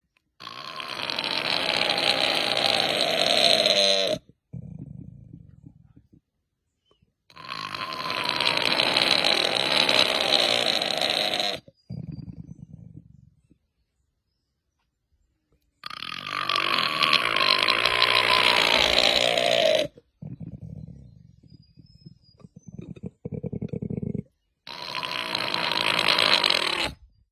Звуки коалы
Рычащий звук недовольной коалы